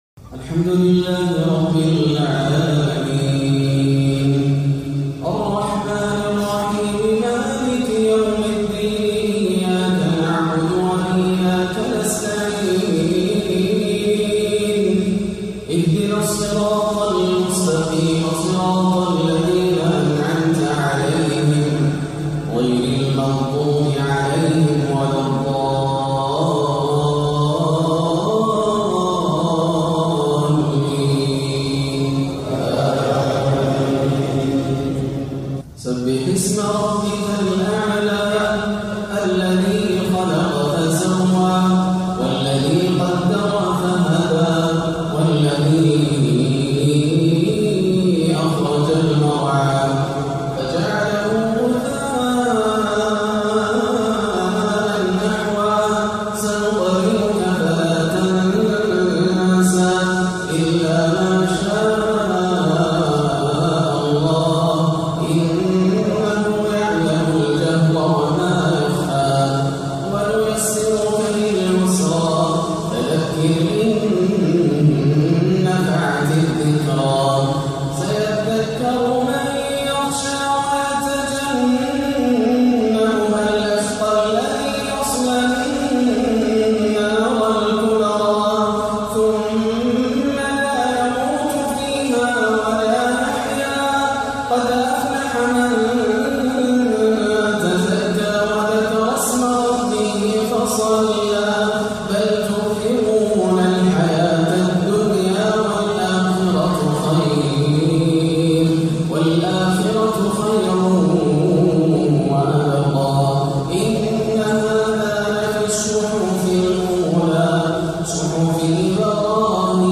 سورتي الأعلى والغاشية بأداء بياتي خلاب - صلاة الجمعة 20-1 > عام 1438 > الفروض - تلاوات ياسر الدوسري